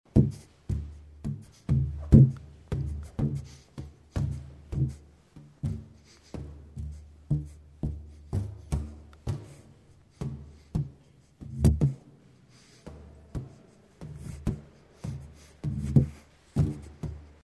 Звук удара рукой по надутому шарику